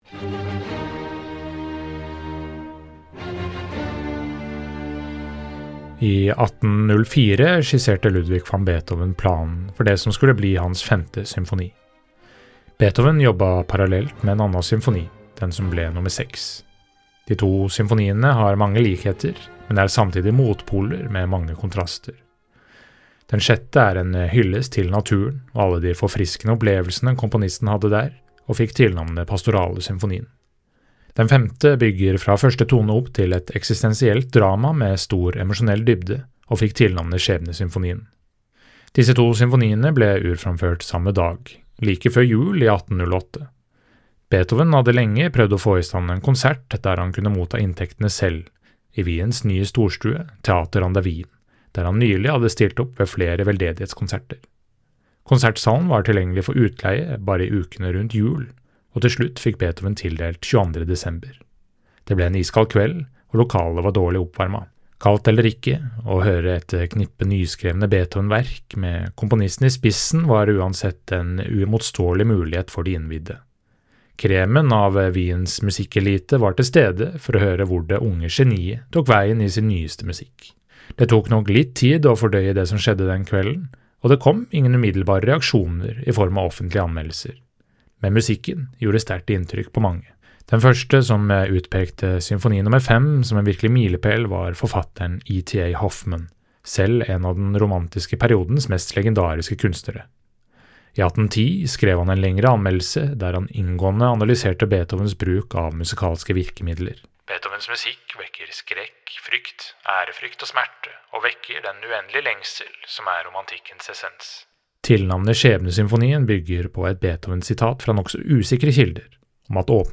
VERKOMTALE: Ludwig van Beethovens Symfoni nr. 5 I 1804 skisserte Ludwig van Beethoven planen for det som skulle bli hans femte symfoni.
VERKOMTALE-Ludwig-van-Beethovens-Symfoni-nr.-5.mp3